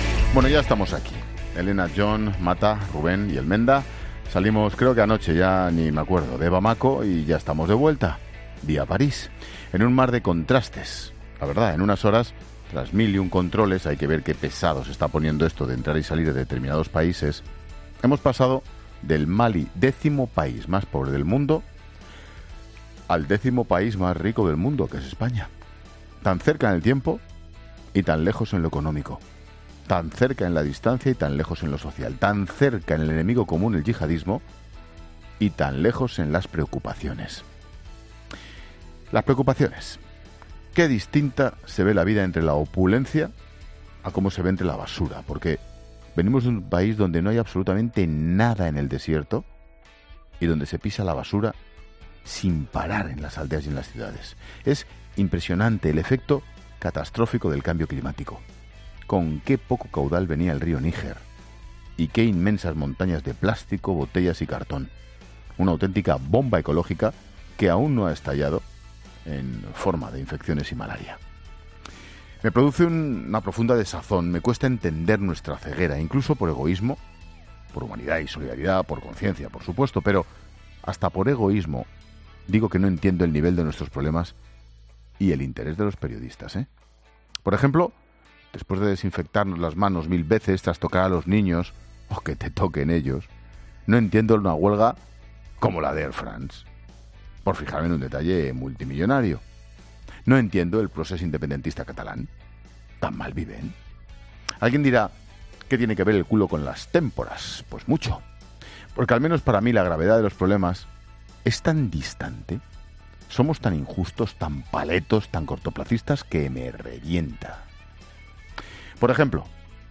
Monólogo de Expósito
El comentario de Ángel Expósito a la vuelta de Bamako (Mali).